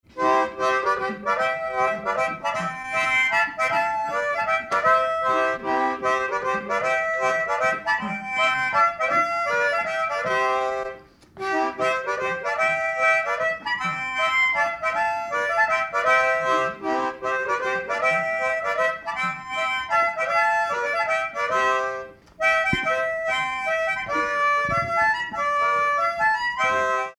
Mémoires et Patrimoines vivants - RaddO est une base de données d'archives iconographiques et sonores.
Mazurka
danse : mazurka
circonstance : bal, dancerie
Pièce musicale inédite